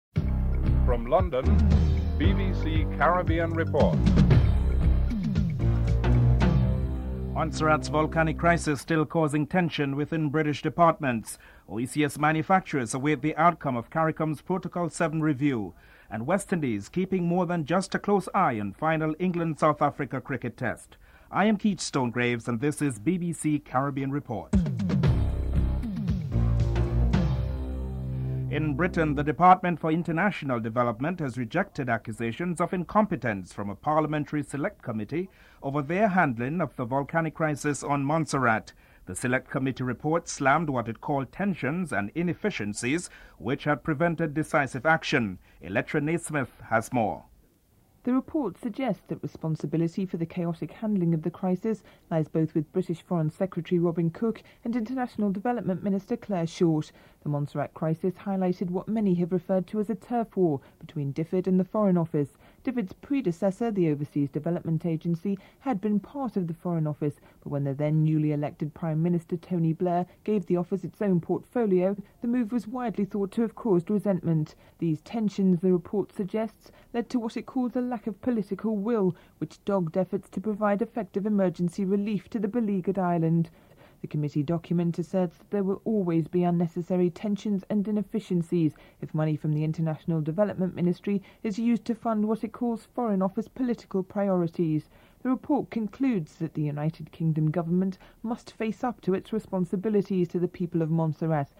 Deputy Leader of the Opposition Labour Party Mike Henry is interviewed (10:05-12:56)